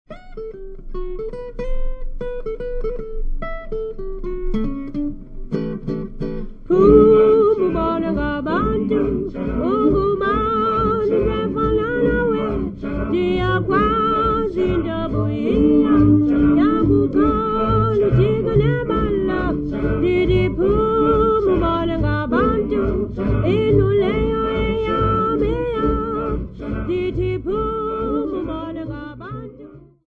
Cape inkspots of Grahamstown
Folk music--Africa
Field recordings
Africa South Africa Grahamstown f-sa
sound recording-musical
A topical song accompanied by guitar.